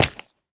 Minecraft Version Minecraft Version snapshot Latest Release | Latest Snapshot snapshot / assets / minecraft / sounds / mob / turtle / egg / jump_egg2.ogg Compare With Compare With Latest Release | Latest Snapshot
jump_egg2.ogg